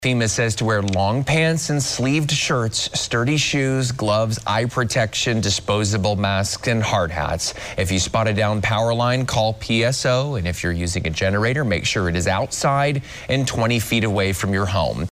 Need tips for cleaning up after storm damage? Reporter